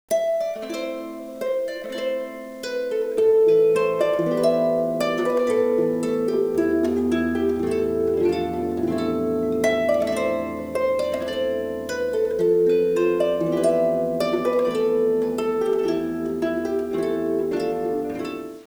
Irish Music
harp
harp.wav